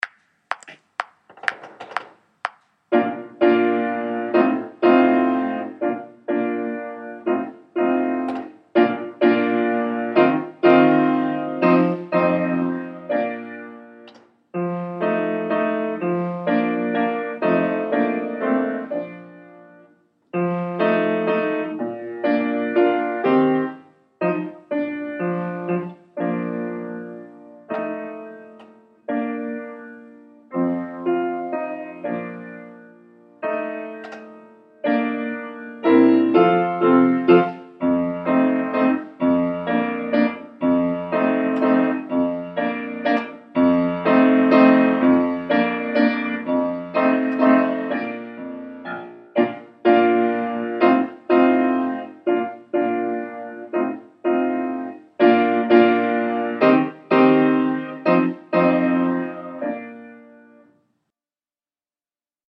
Concertpiece Piano only